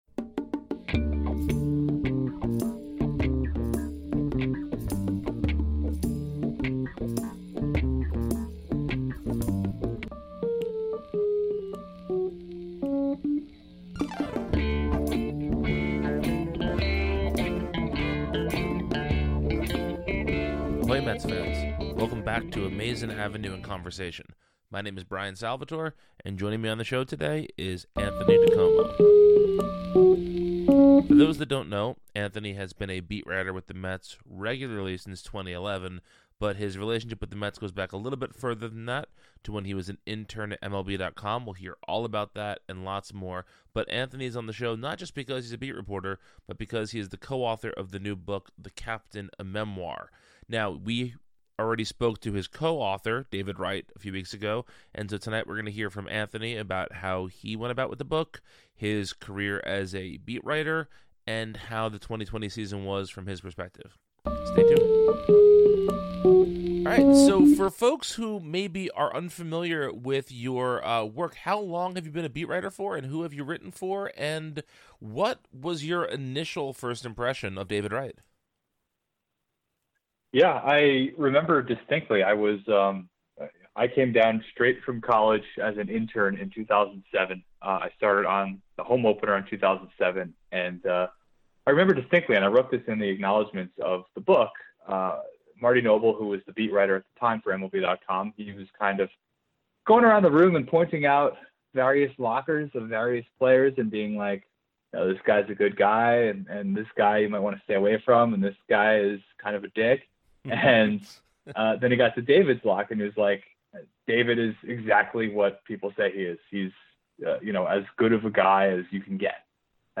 Welcome to Amazin’ Avenue in Conversation, a podcast from Amazin’ Avenue where we invite interesting people on the show to talk about themselves, the Mets, and more.